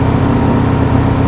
Engines
1 channel